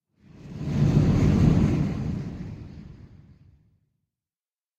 ambient / cave
cave11.ogg